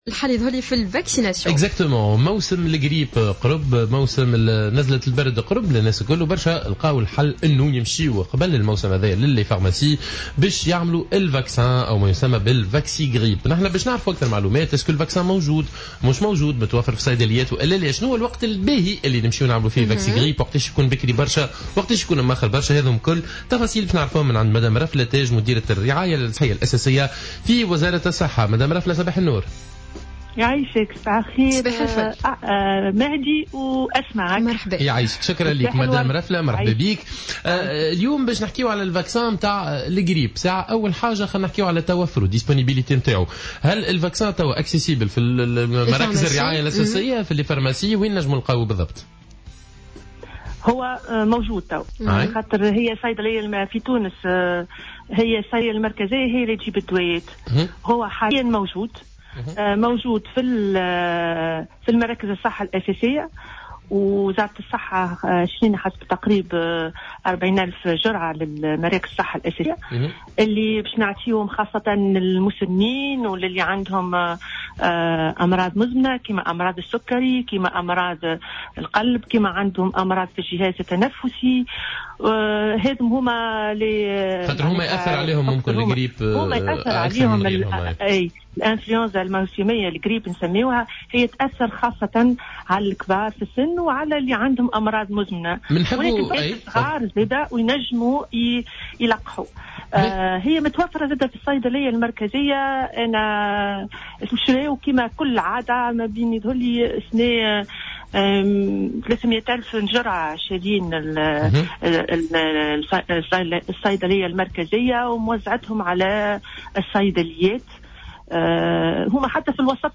في مداخلة لها على الجوهرة "اف ام"